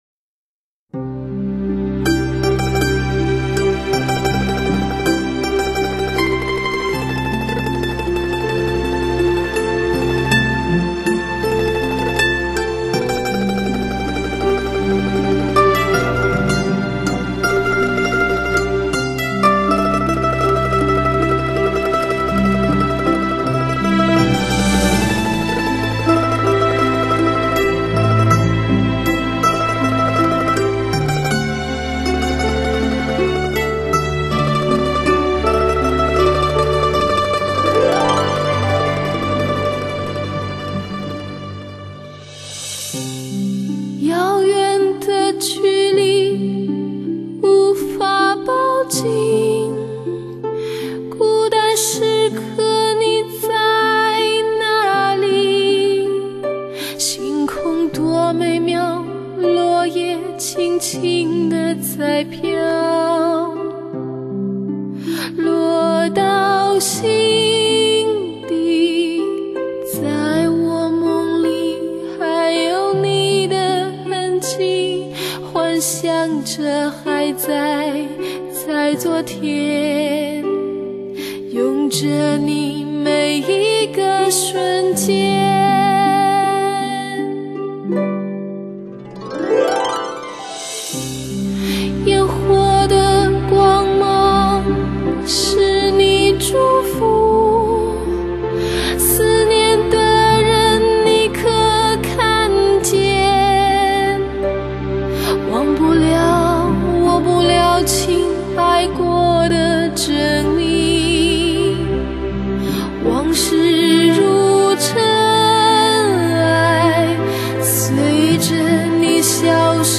类  　别：DSD